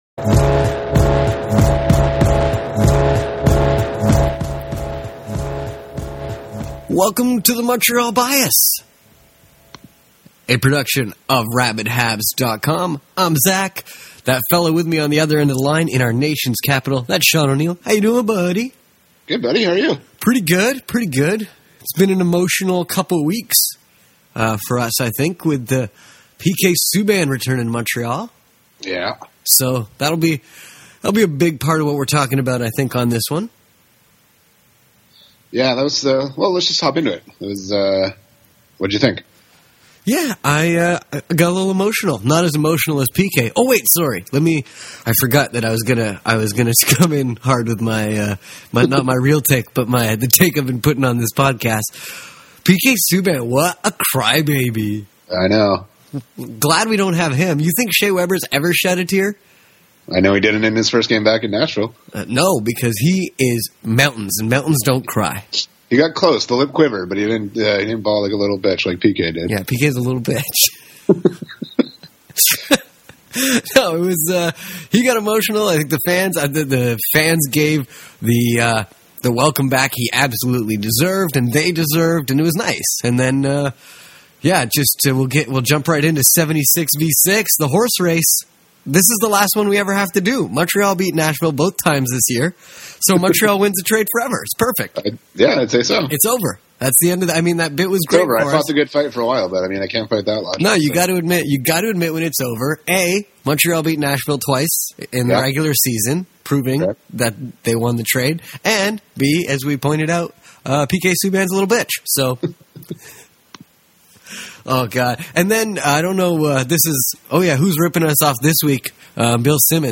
The gents are back this week to talk about PK Subban’s return to Montreal, the lackluster NHL trade deadline, and out of breath Phil Kessel.